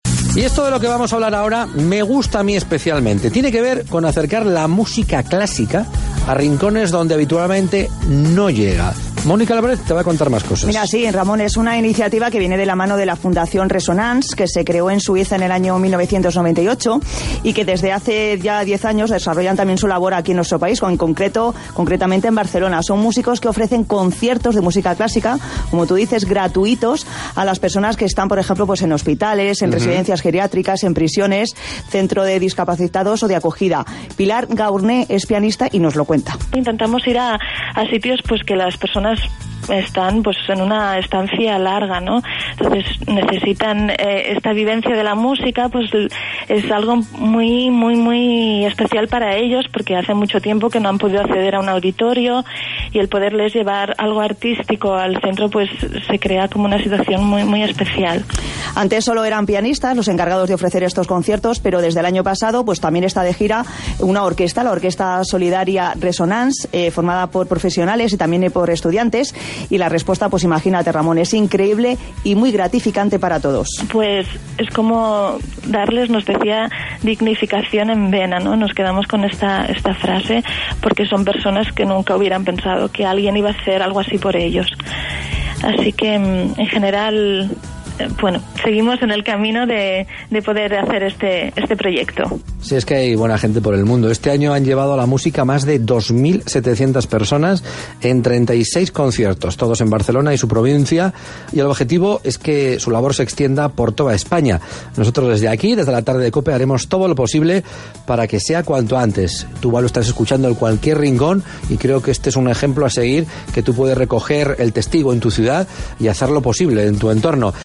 La Tarde con Ramón García: entrevista